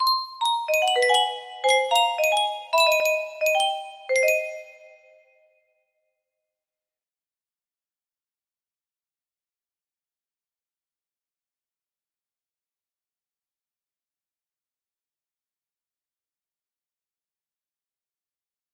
67 41 21 music box melody